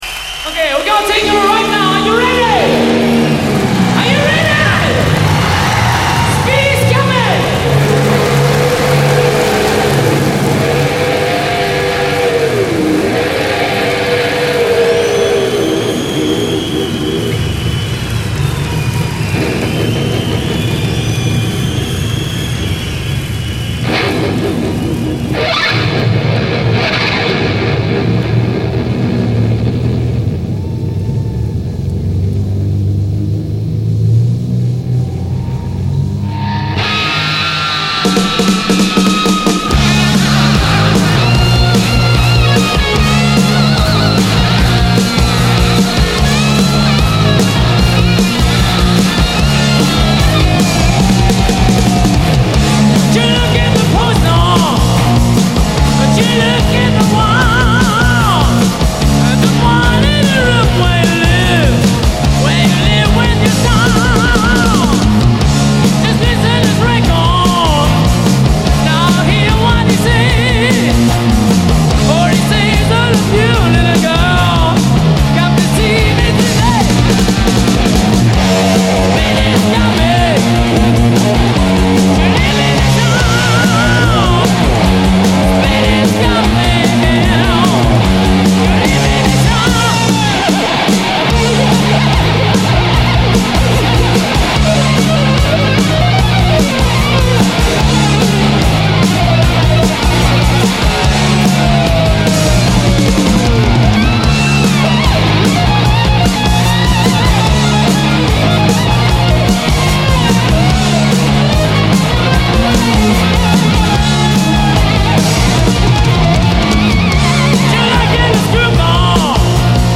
enregistrait à Tokyo un double-album au Sun-Plaza Hall.
guitariste absolument flamboyant
et malgré une rythmique parfois un peu lourdingue